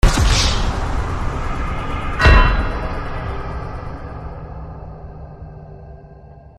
• Качество: 320, Stereo
без слов
короткие
из игры